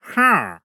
Minecraft Version Minecraft Version 25w18a Latest Release | Latest Snapshot 25w18a / assets / minecraft / sounds / mob / wandering_trader / yes1.ogg Compare With Compare With Latest Release | Latest Snapshot